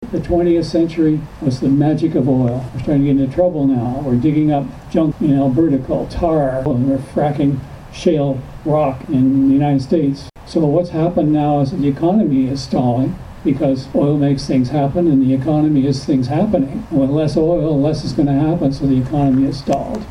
After about an hour of placard-waving surrounding the junction of Highways 60 and 41, the group retired to the serenity of the amphitheatre in Gerald Tracey Park to hear from a variety of speakers including one pragmatic Upper Tier radical.